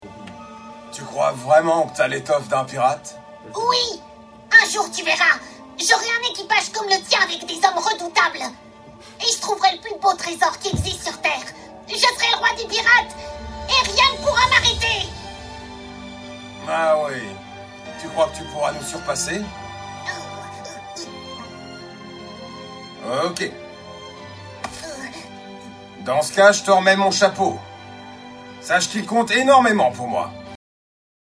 Voix de Luffy - ONE PIECE - exercice doublage
5 - 65 ans - Mezzo-soprano